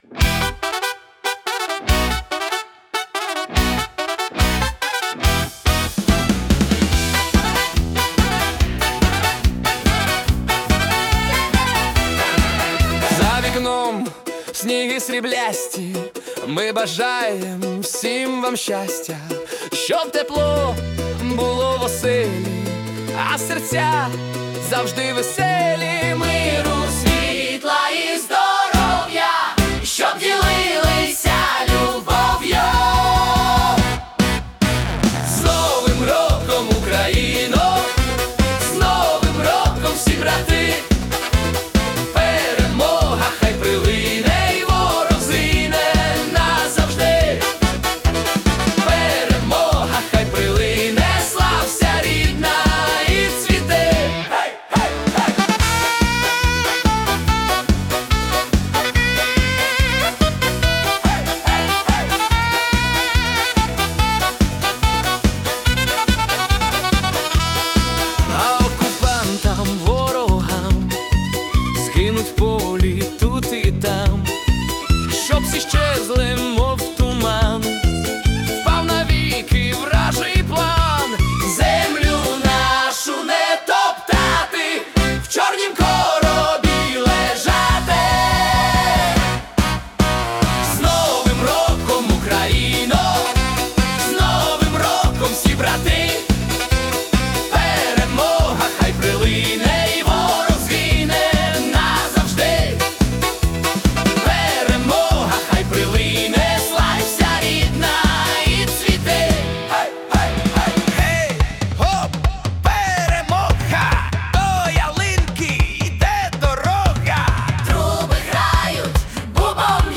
🎵 Жанр: Фолк-поп / Полька / Ска
Фолк-вибух на 140 BPM